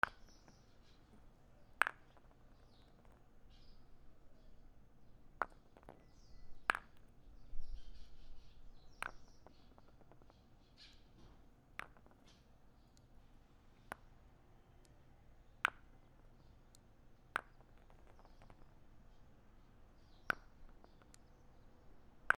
けん玉を地面に落とす
/ G｜音を出すもの / Ｇ-15 おもちゃ